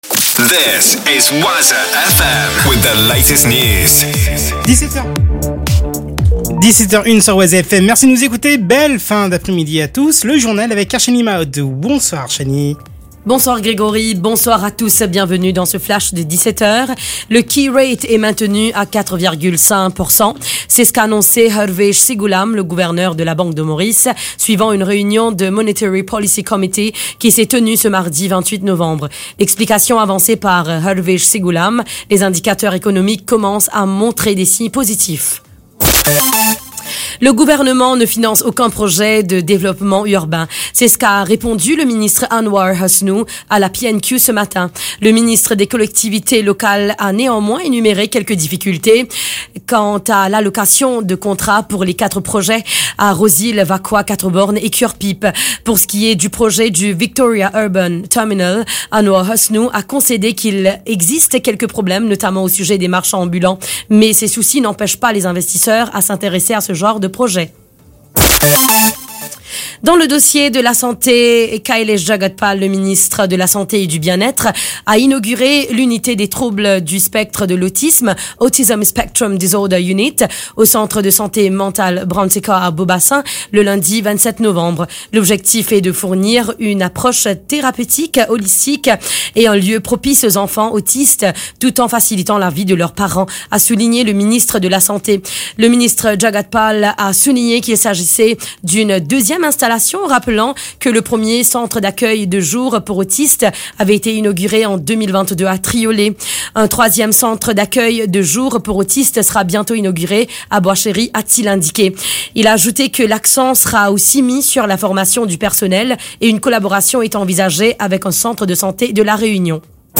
NEWS 17H - 28.11.23